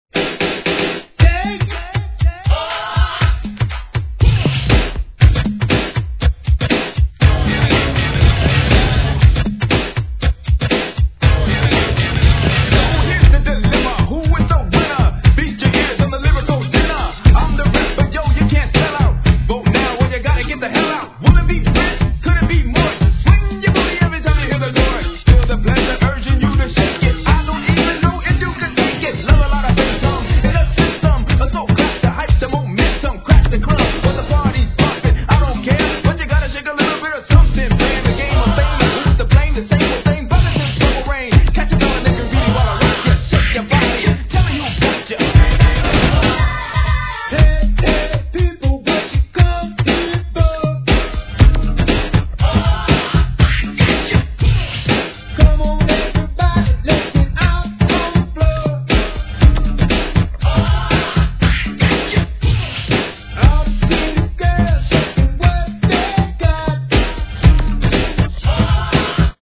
SOUL/FUNK/etc...
BOOM MIXはもろにHIP HOP BEATでどのMIXも楽しめます!!